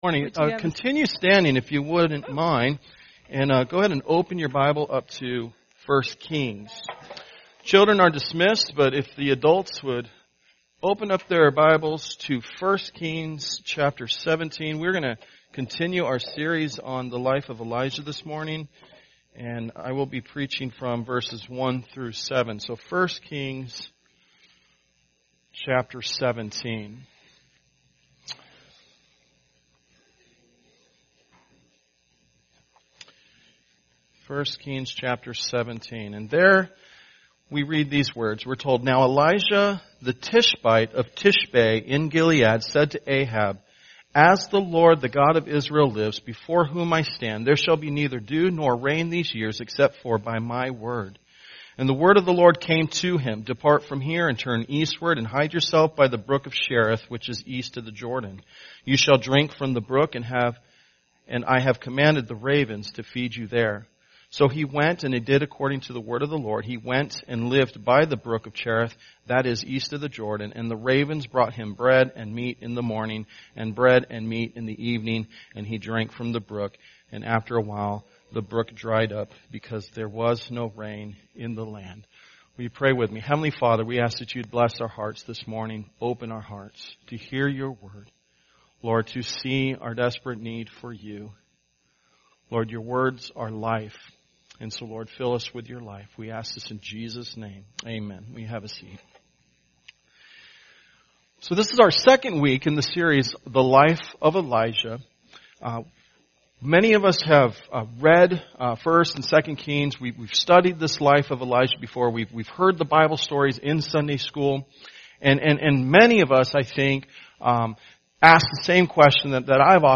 When we first meet Elijah in Scripture, we see him taking a stand against Israel and king Ahab. In this week’s sermon, we see how we are to take a stand with God as well.
Sunday Worship